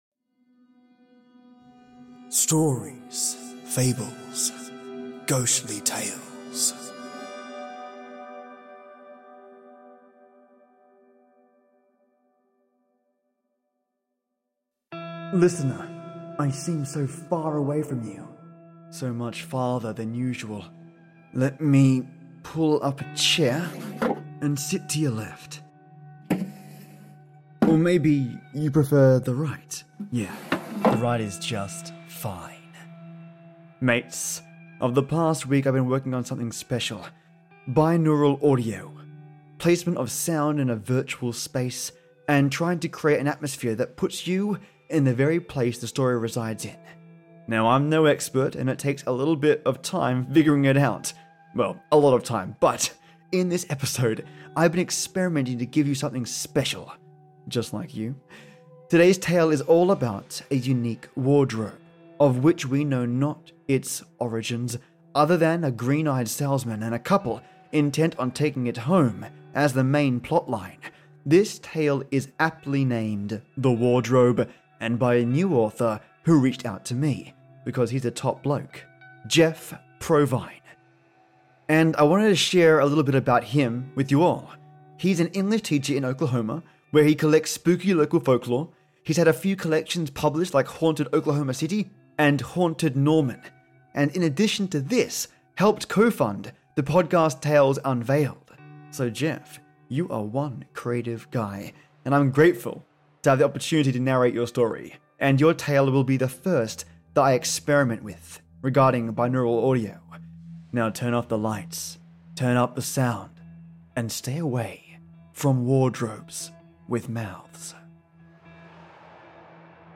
Placement of sounds in a virtual space, and trying create an atmosphere that puts you in the very place the story resides in.